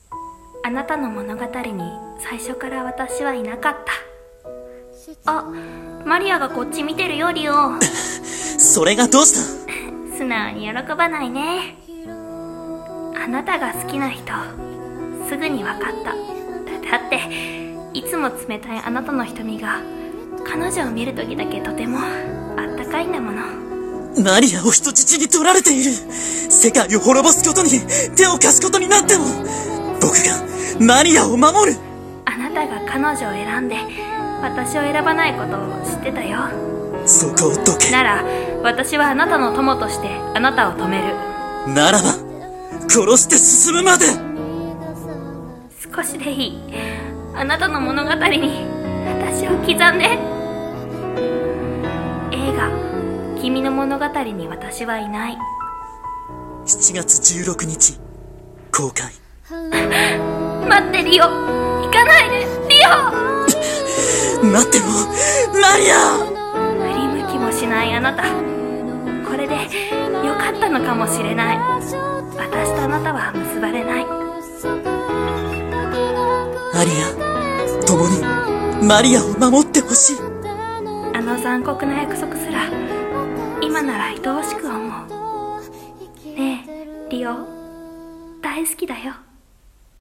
映画予告風声劇「君の物語に私はいない」